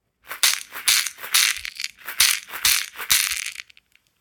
ひょうたん底 バスケットマラカス アフリカ 民族楽器 （p505-34） - アフリカ雑貨店 アフロモード
ブルキナファソで作られたバスケット素材の素朴なマラカスです。
やさしいナチュラルな乾いた音を出します。
説明 この楽器のサンプル音 原産国 ブルキナファソ 材質 水草の茎、ひょうたん、木の実 サイズ 高さ：16cm 最大幅：9cm 重量 70g コメント 一点現品販売品です。